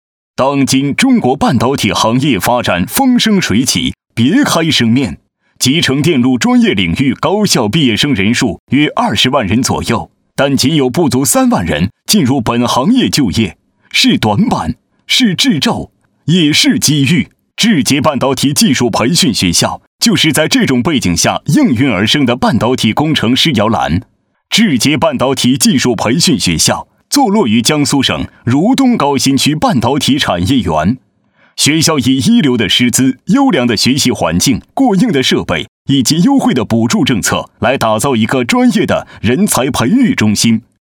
大气洪亮、厚实稳重男音，擅自专题汇报、党建、宣传片等题材。